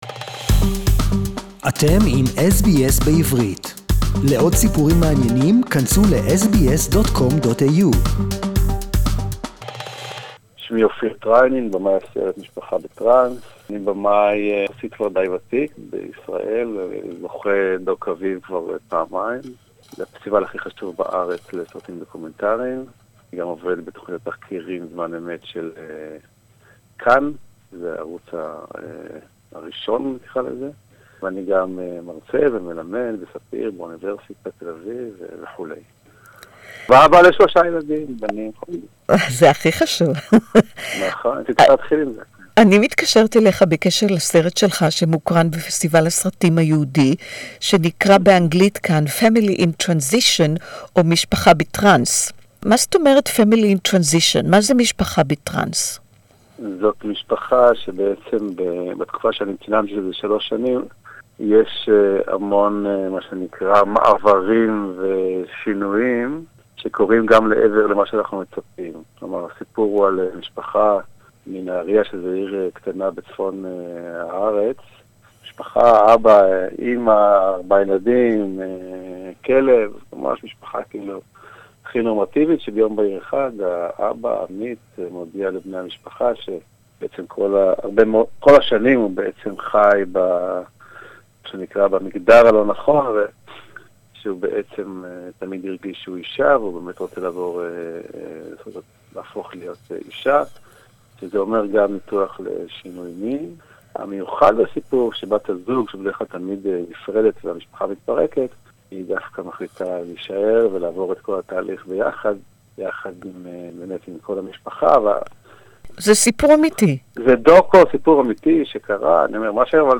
Winner Best Israeli Documentary 2018 interview in Hebrew